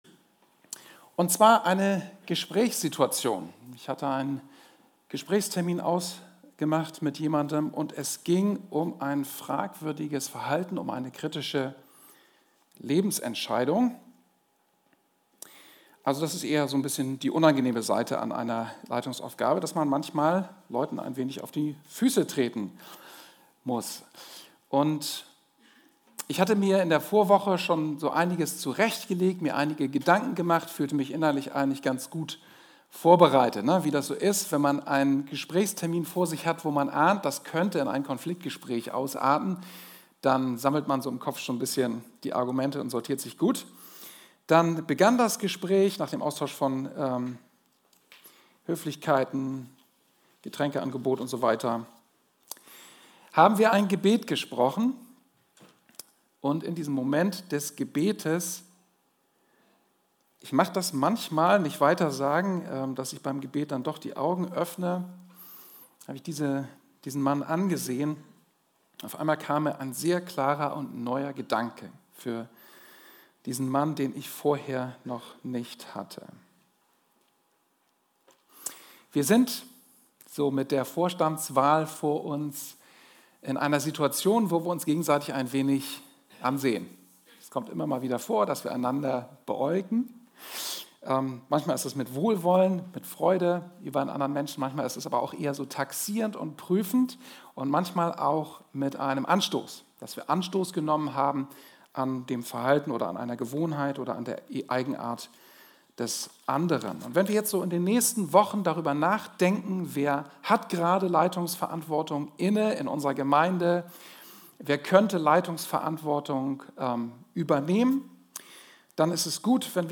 Die Online-Präsenz der freien evangelischen Andreas-Gemeinde Osnabrück
PREDIGTEN